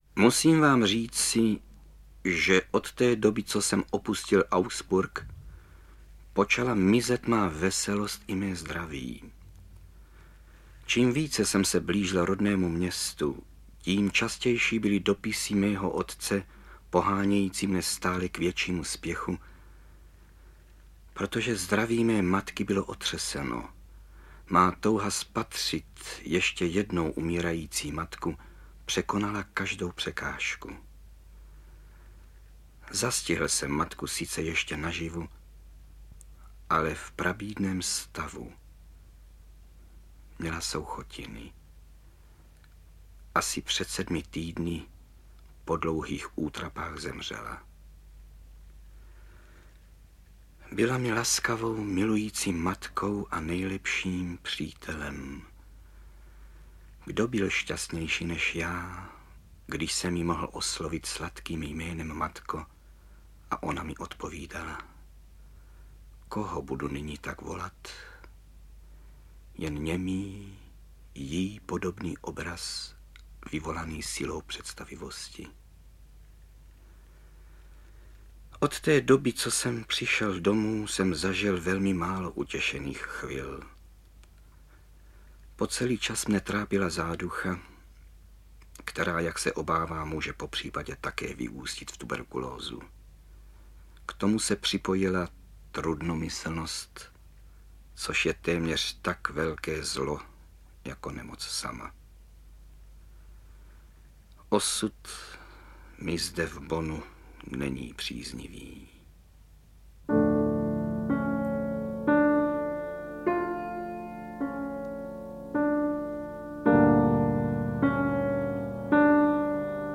Nesmrtelná slova Ludwiga van Beethovena - úryvky z dopisů, konverzačních sešitů, vzpomínek současníků z let 1792, 1802, 1812, 1819 čte Karel Höger Mluvené slovo doplňují a propojují ukázky autorovy hudby.
AudioKniha ke stažení, 1 x mp3, délka 43 min., velikost 39,7 MB, česky